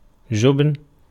ملف تاريخ الملف وصلات معلومات الصورة (ميتا) Ar-جبن.ogg  (Ogg Vorbis ملف صوت، الطول 1٫1ث، 113كيلوبيت لكل ثانية) وصف قصير ⧼wm-license-information-description⧽ Ar-جبن.ogg English: Pronunciation of word "جبن" in Arabic language. Male voice. Speaker from Tiznit, Morocco.